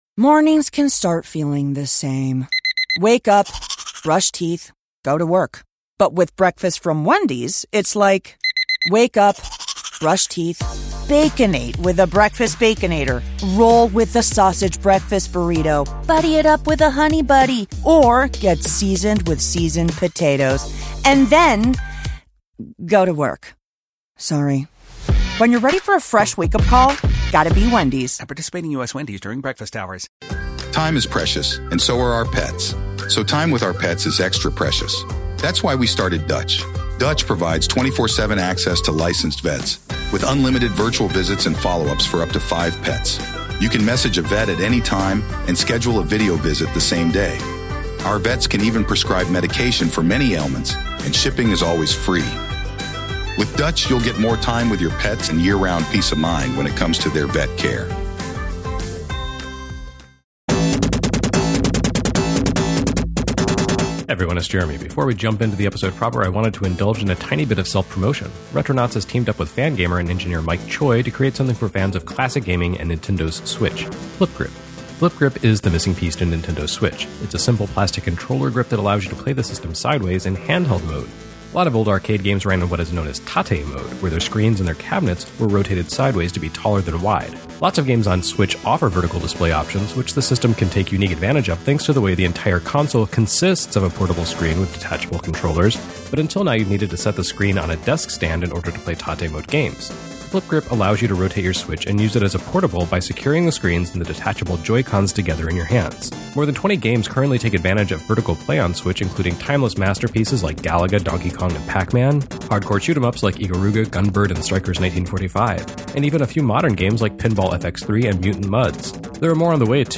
Travel back in time to Midwest Gaming Classic